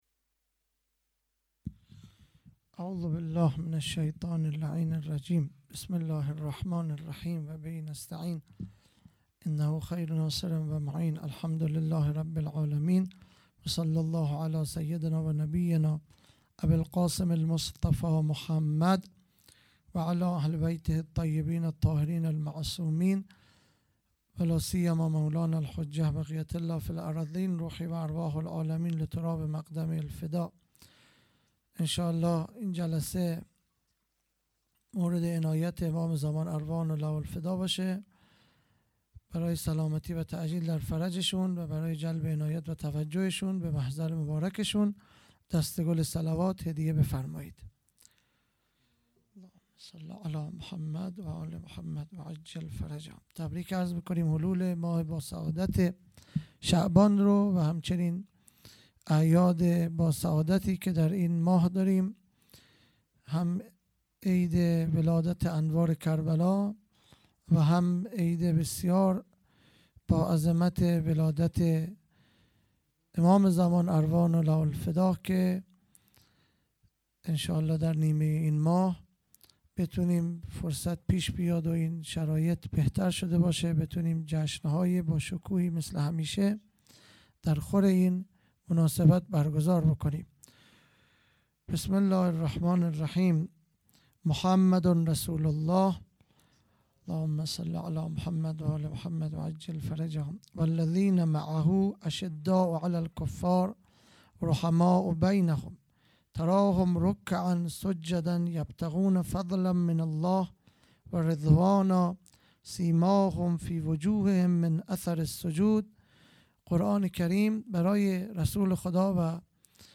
خیمه گاه - هیئت بچه های فاطمه (س) - سخنرانی|به مناسبت میلاد سرداران کربلا